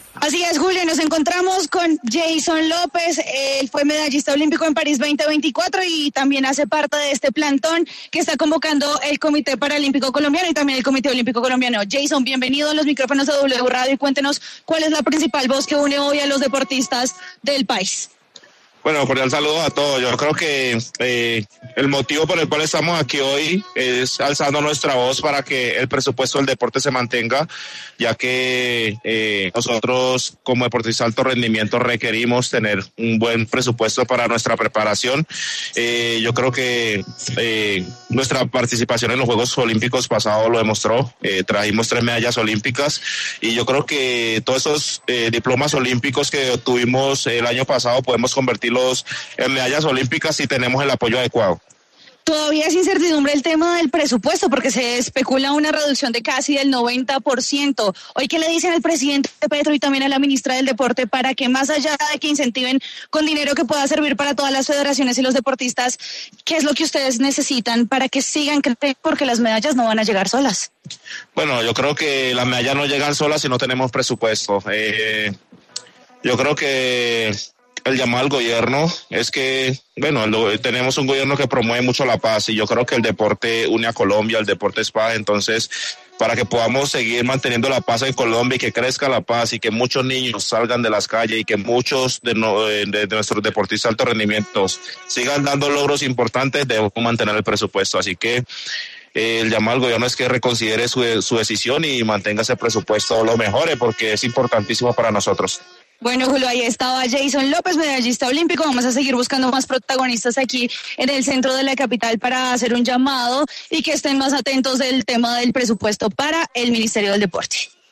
El pesista colombiano Yeison López, quien ganó la medalla de plata en los Juegos Olímpicos de París 2024, conversó con La W apropósito de la protesta que adelantan deportistas en la Plaza de Bolívar de Bogotá por la disminución del presupuesto para el deporte en 2026.